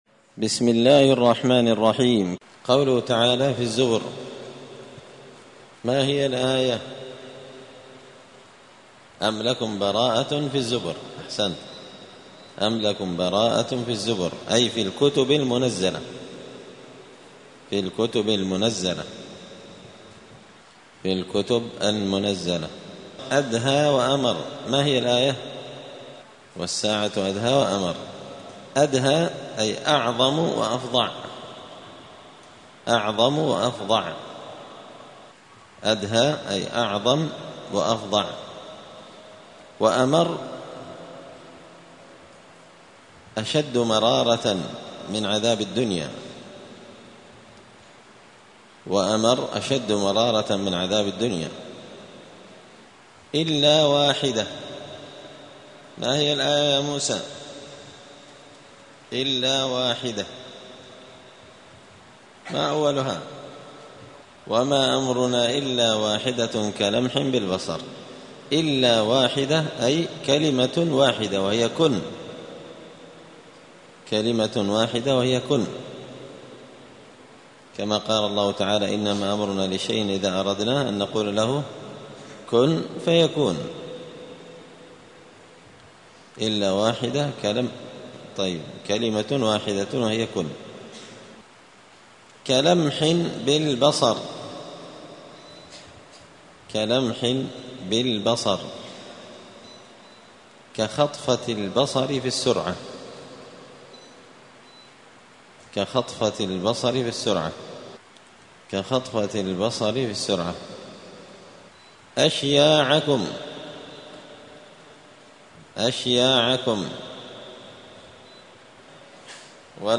زبدة الأقوال في غريب كلام المتعال الدرس السابع والسبعون بعد المائة (177)